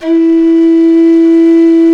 SULING E3.wav